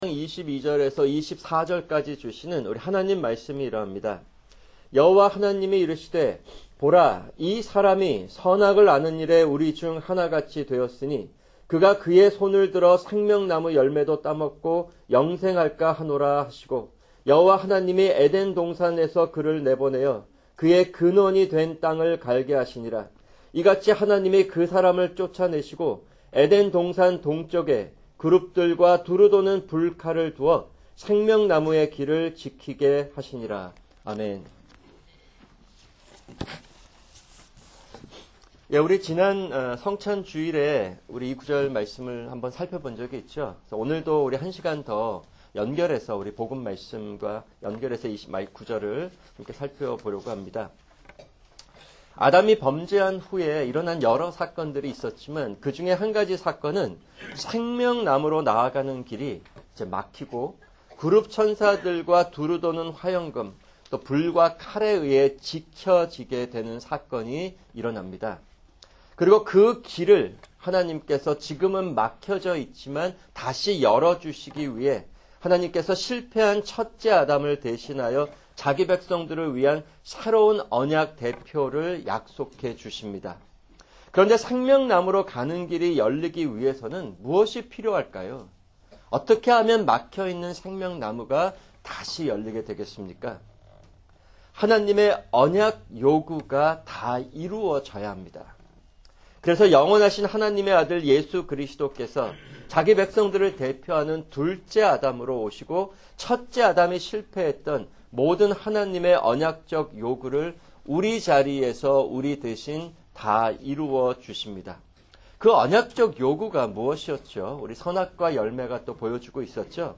[주일 설교] 창세기 3:22-24(2)